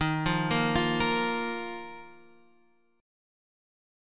D#sus2 chord